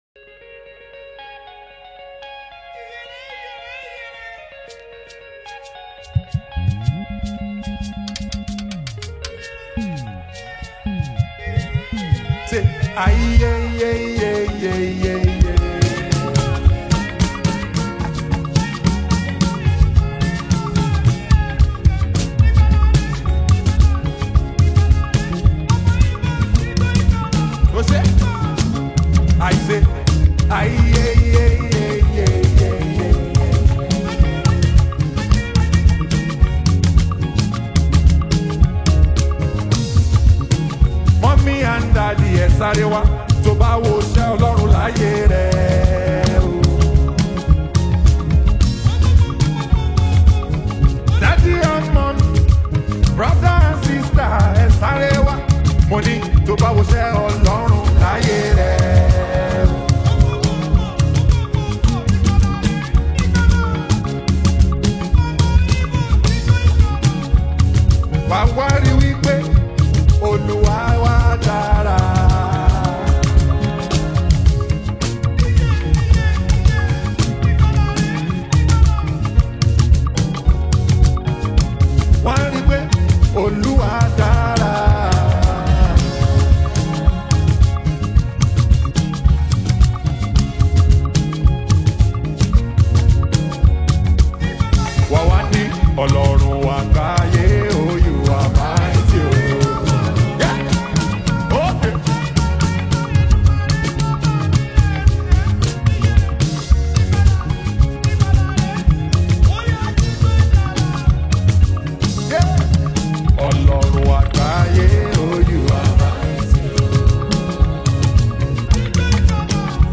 Nigerian Christian/Gospel